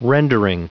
Prononciation du mot rendering en anglais (fichier audio)
Prononciation du mot : rendering